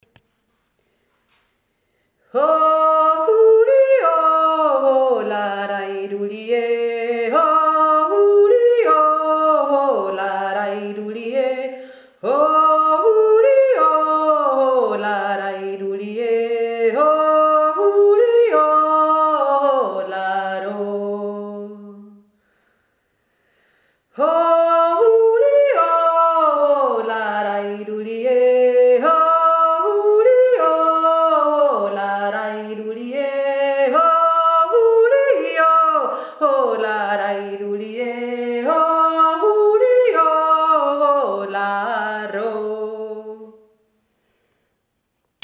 Dortmund jodelt 2024